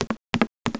stairs.wav